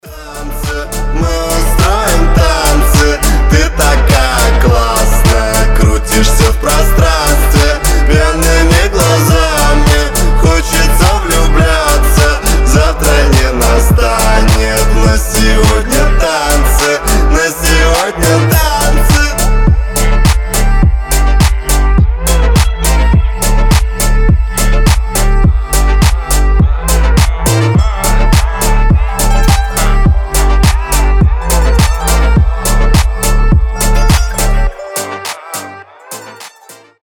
Танцевальные рингтоны
Клубные рингтоны
клубняк , зажигательные